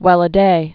(wĕlə-dā)